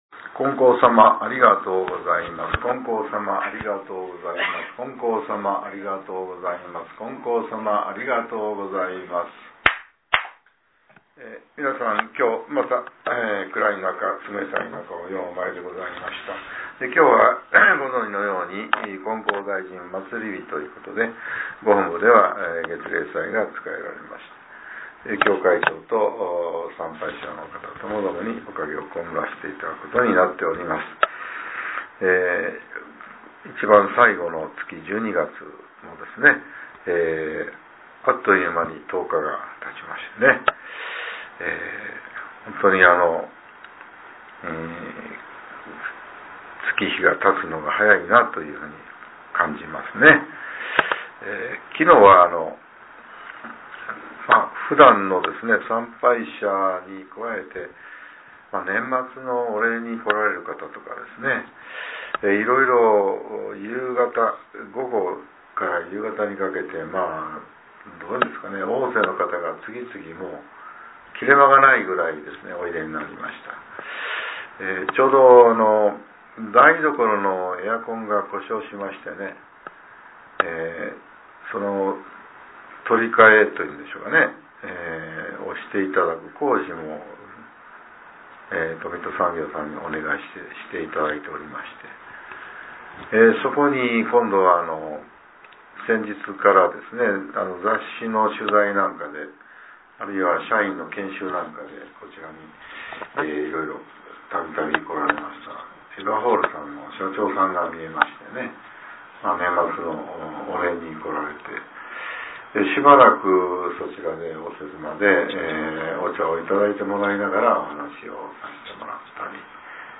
令和６年１２月１０日（朝）のお話が、音声ブログとして更新されています。